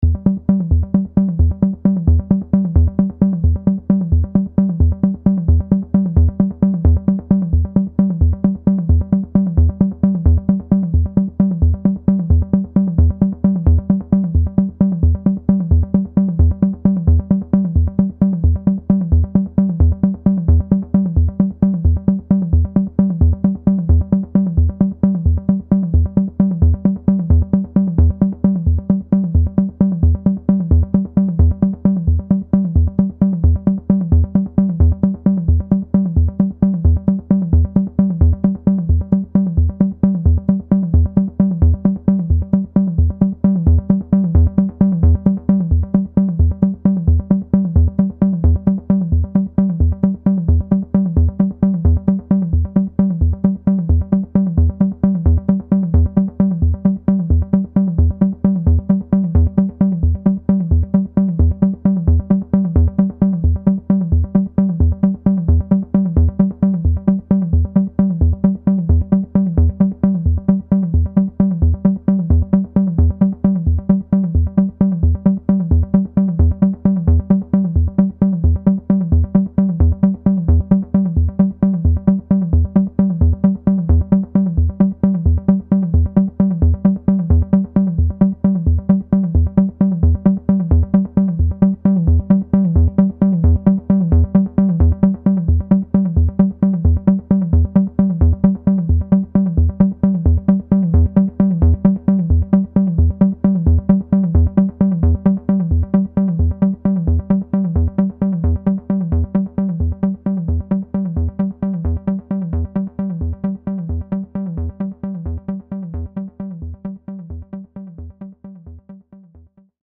The CD contains a single track, consisting of a recording of a sequence of four notes repeated many times over, played on a home-made analogue synthesizer by a computer. Over the course of the recording, the synthesizer's controls were altered manually to produce very slow, gradual changes in the synthesizer's timbre. The recording was then processed using delay and equalisation effects, the controls of which were also altered slowly to produce further gradual changes.